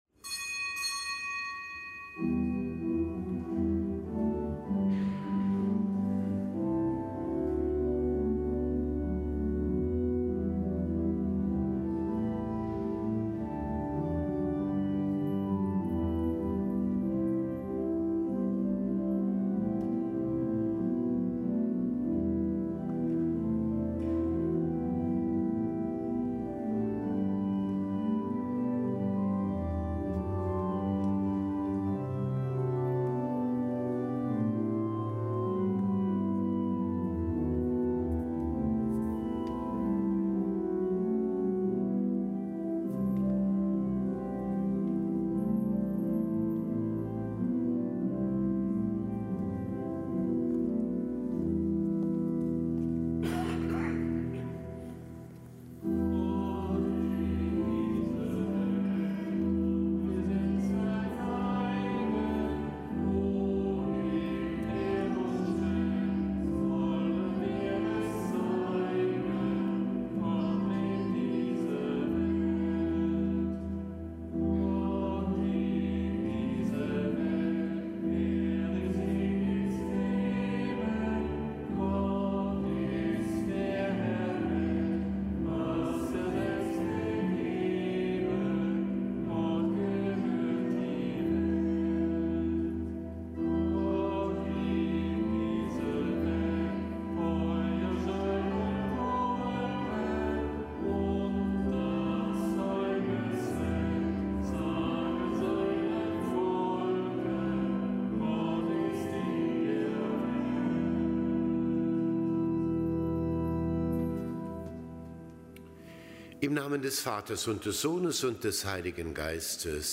Kapitelsmesse aus dem Kölner Dom am Dienstag der achtundzwanzigsten Woche im Jahreskreis. Am nicht gebotenen Gedenktag des Heiligen Kallistus I. , einem Papst und Märtyrer.
Zelebrant: Weihbischof Dominikus Schwaderlapp Nach oben